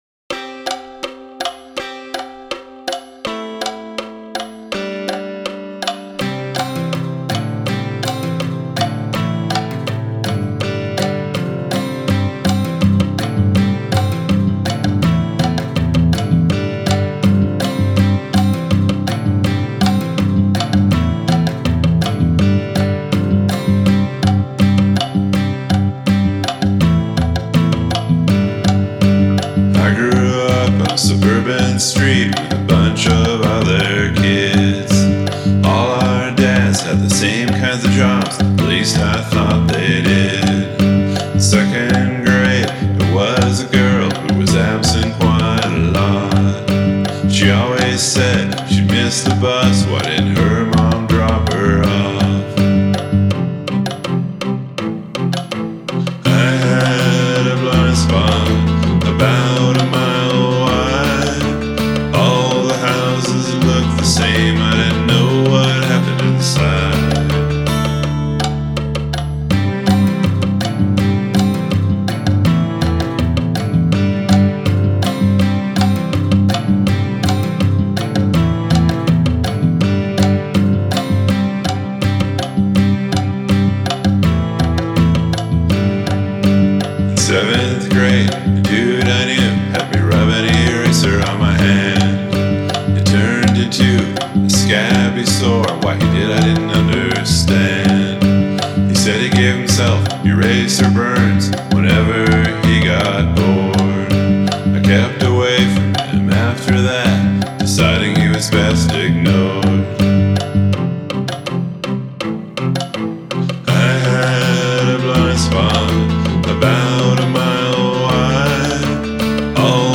Hand percussion